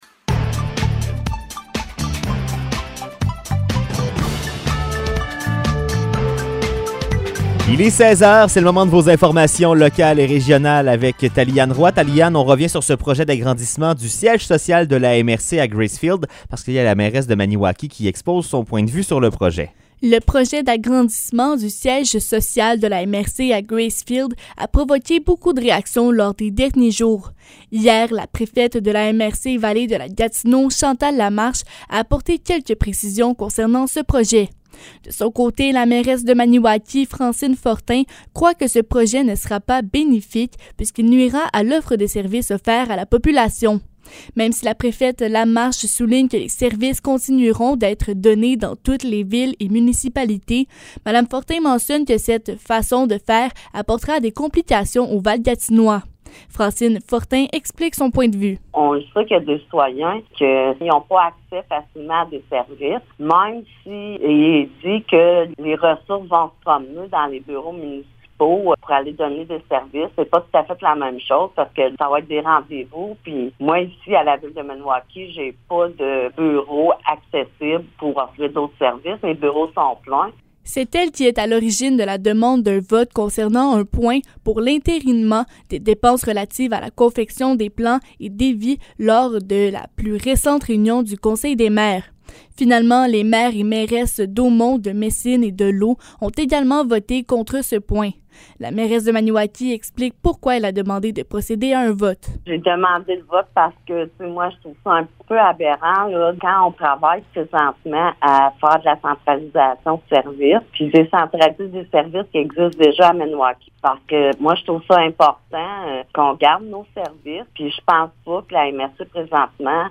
Nouvelles locales - 23 juin 2022 - 16 h